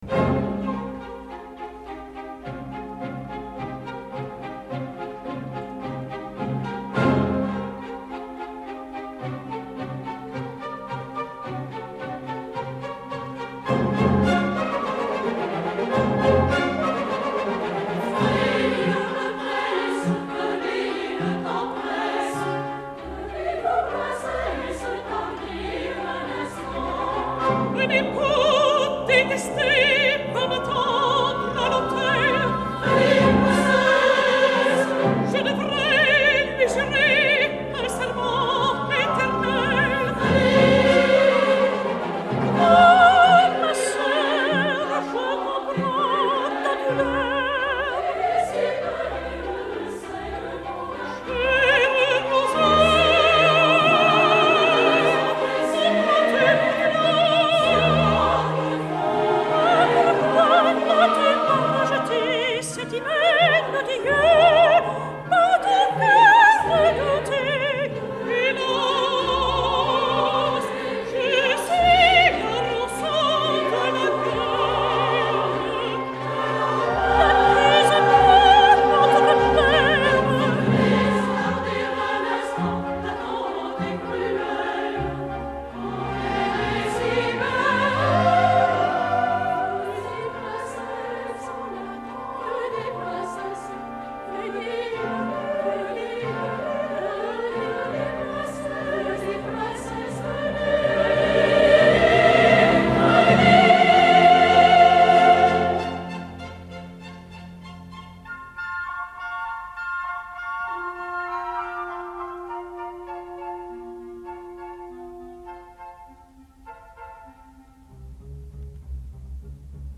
类别: 三幕歌剧
国王 抒情男低音
马格丽德 公主 女中音
罗森 公主妹妹 女高音